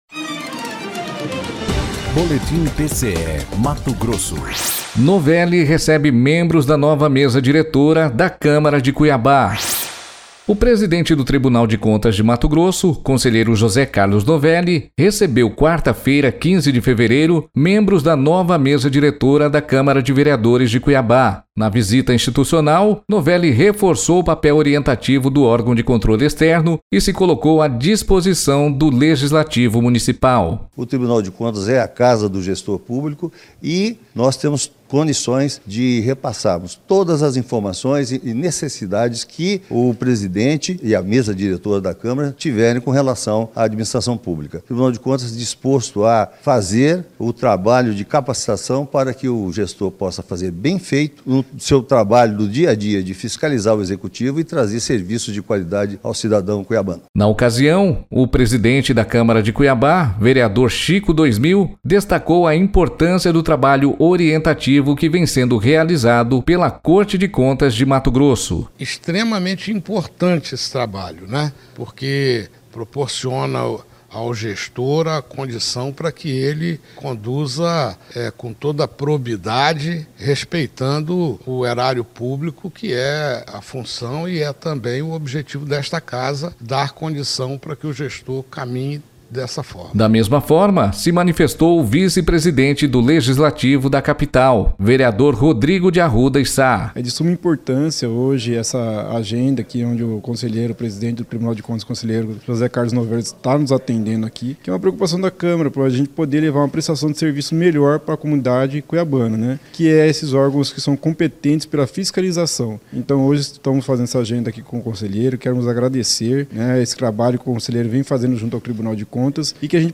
Sonora: Chico 2000 – vereador presidente da Câmara de Cuiabá
Sonora: Rodrigo de Arruda e Sá - vereador vice-presidente da Câmara de Cuiabá